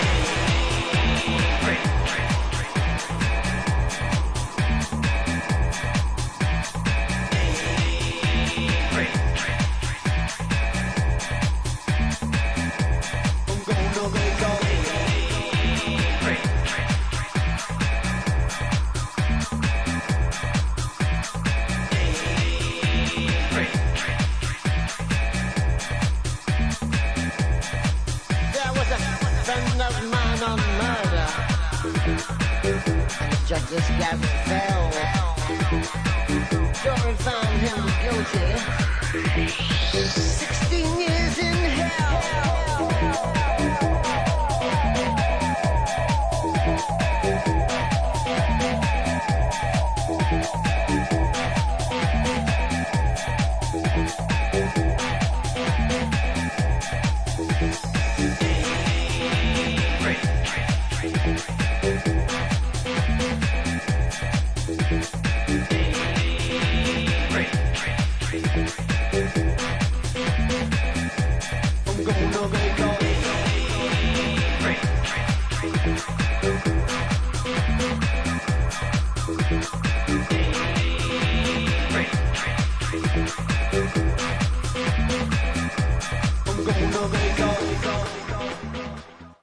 のブートレグ・ハウス・リミックス！！